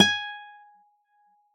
古典吉他谐音 " Gt Cl Hrm04f,4s(78)~v01
描述：用CAD E100话筒录制的雅马哈Eterna古典原声吉他的谐音的1次拍摄样本。 本包样本的注意事项： 谐音是在乐器每根弦的第4、5、7和12个音符上演奏的。
标签： 声学的 多重采样 1次 速度 吉他
声道立体声